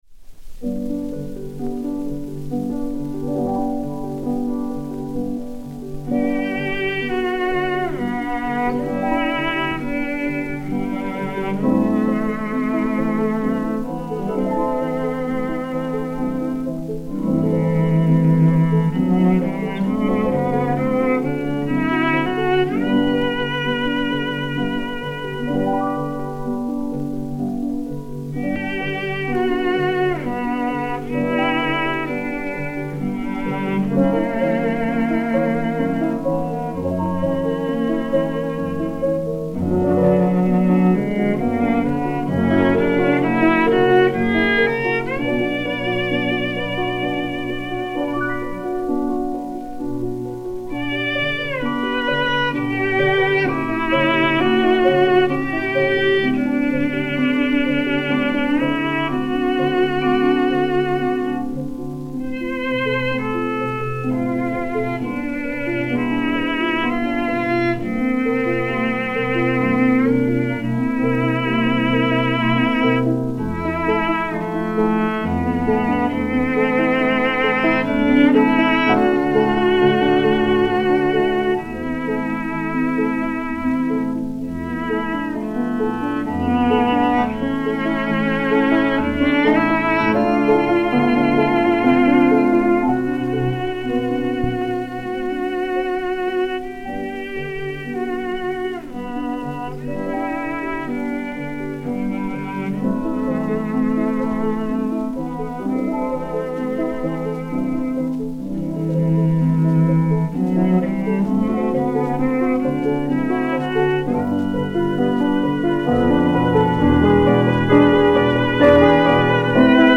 (violoncelle) et 2 Pianos
Lumen 30.092, mat. XL 206, enr. le 10 mars 1943